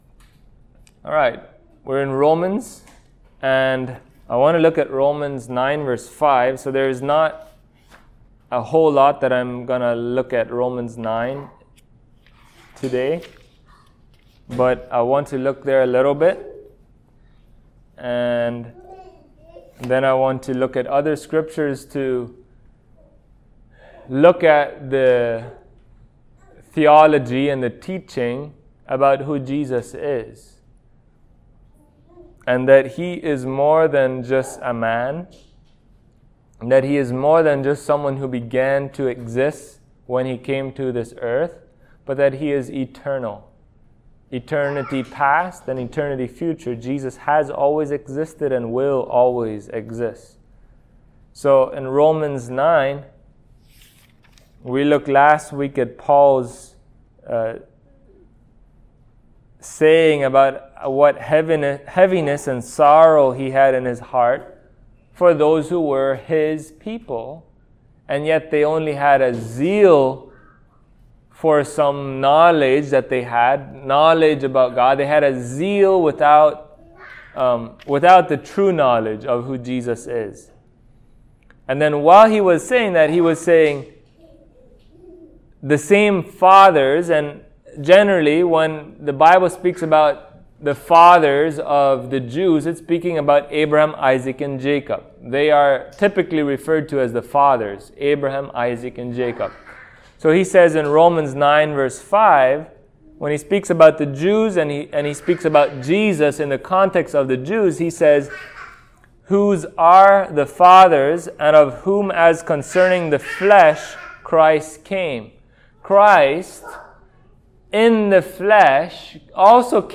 Passage: Romans 9:5 Service Type: Sunday Morning